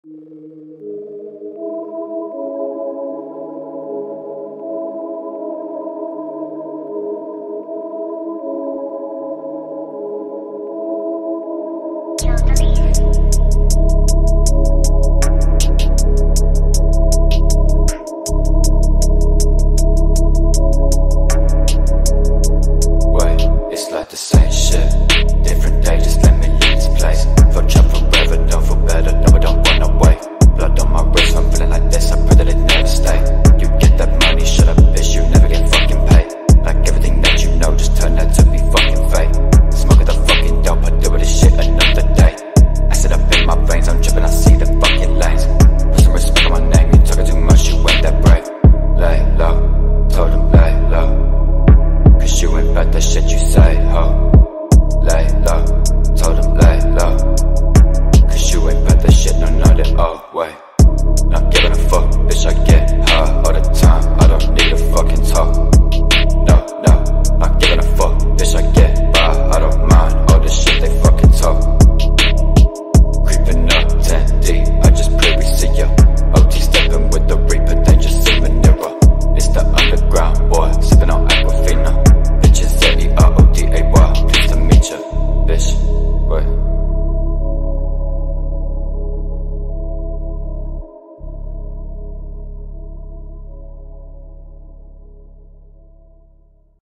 آهنگ بیس دار برای ماشین